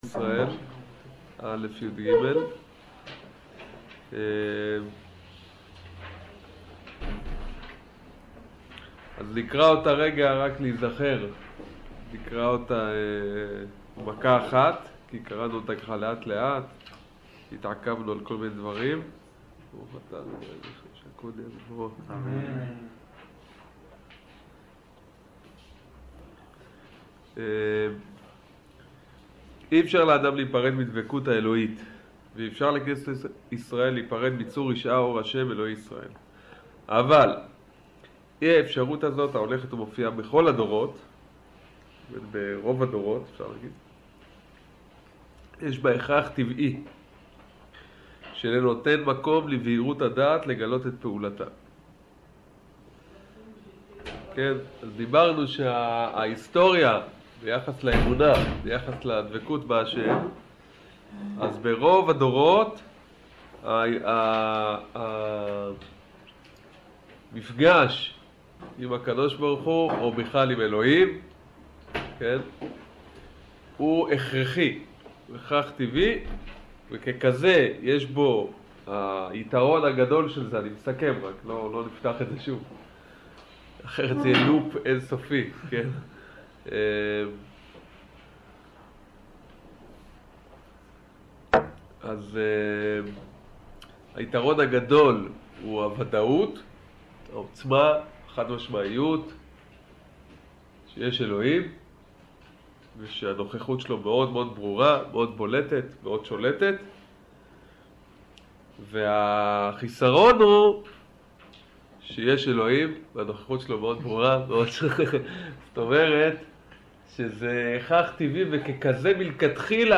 שיעור איש ואישה חלק ו'